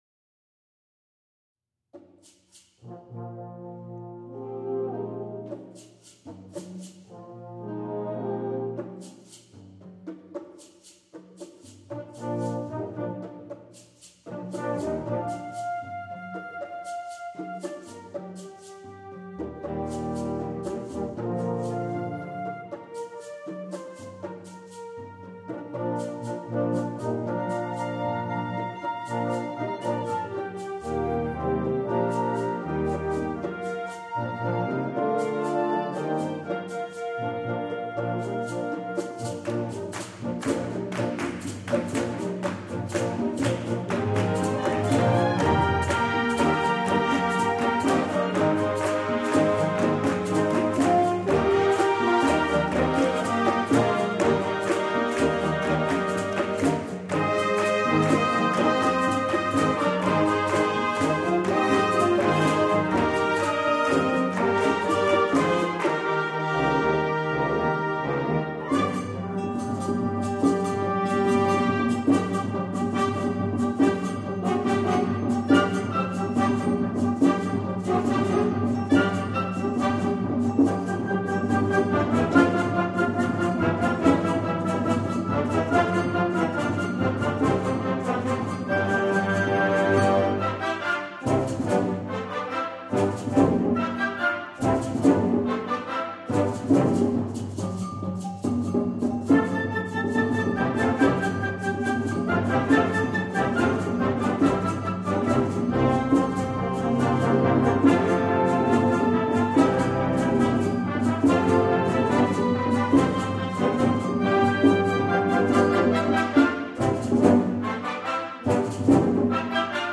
Genre: Band
Flute/Piccolo
Oboe
Bassoon
Bass Clarinet
Baritone Saxophone
Tuba
Timpani (4 drums)
Percussion 2 (2 tom-toms, xylophone)
Percussion 3 (2 congas, chimes, tam-tam)